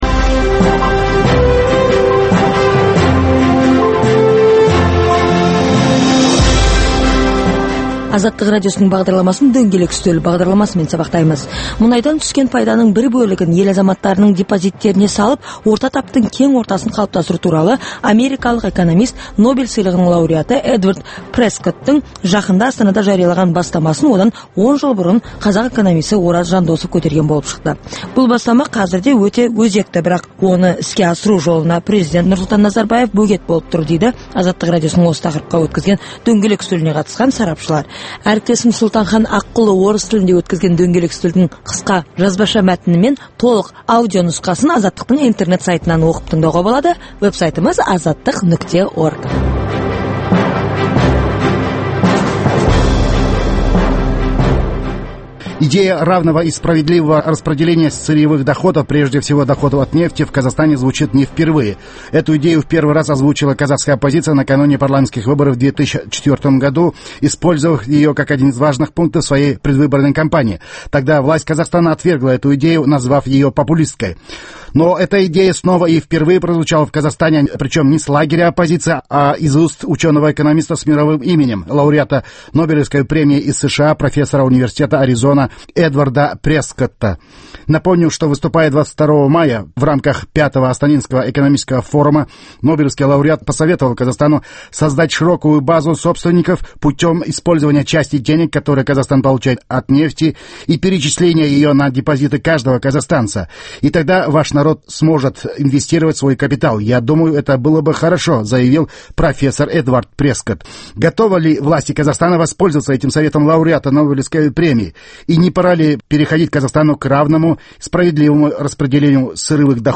Дөңгелек үстел